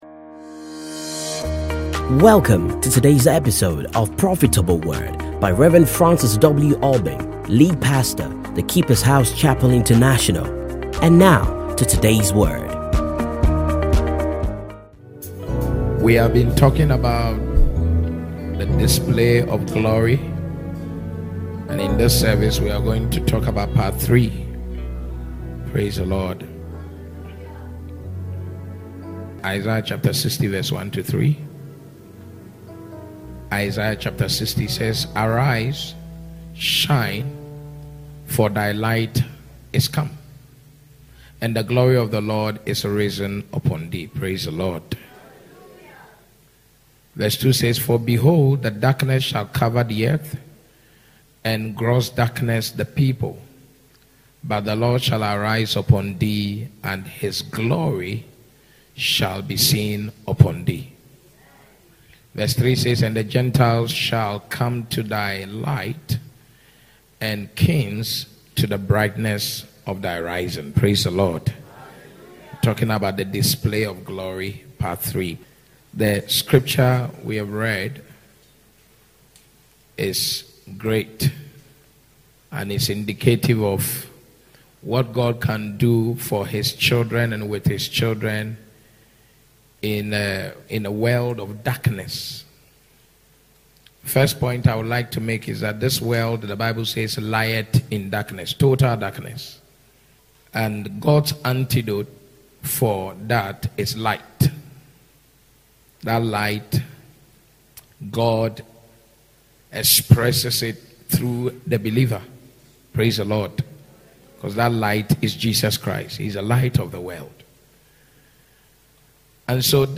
Stay Connected And Enjoy These Classic Sermons